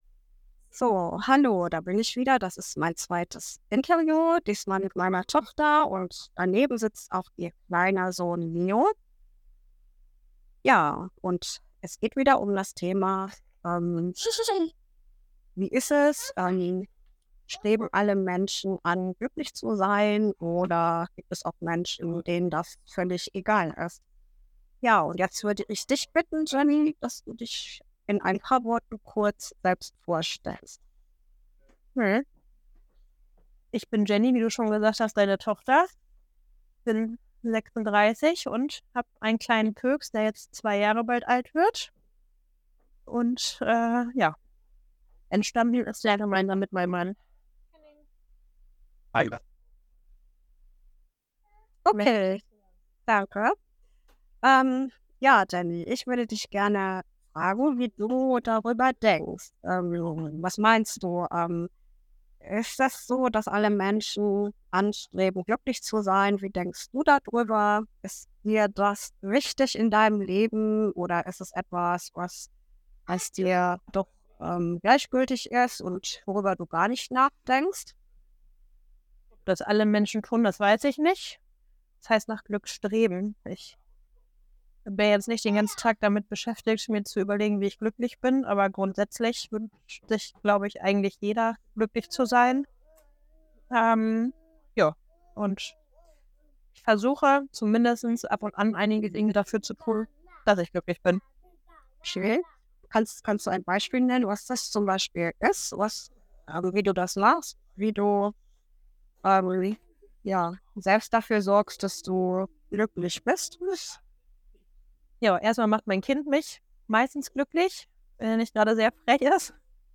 Interviewreihe zum Thema Glück